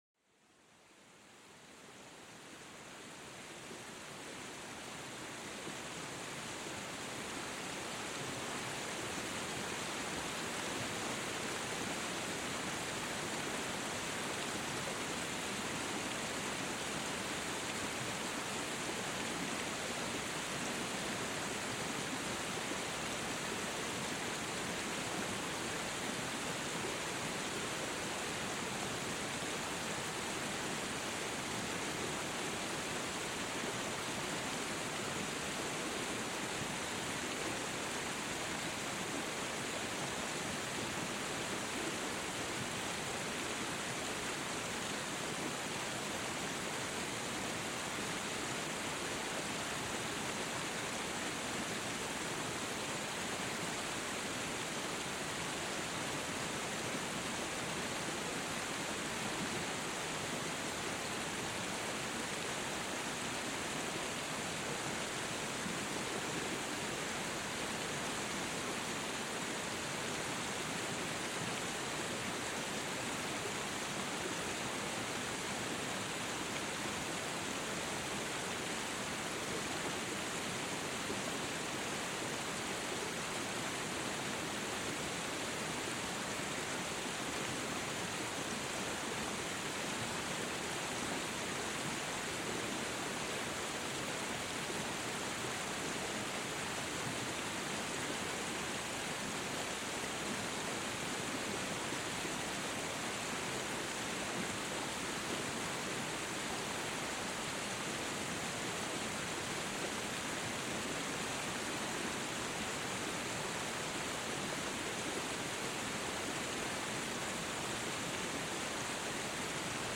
Sumérgete en el mundo sonoro de una cascada poderosa, donde el tumulto del agua se convierte en una sinfonía natural. Déjate envolver por la intensidad de este paisaje sonoro, donde cada gota cuenta una historia.